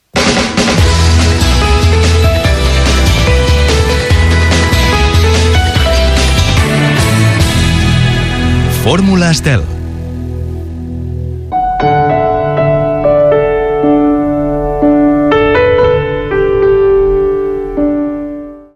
Careta del programa i tema musical Gènere radiofònic Musical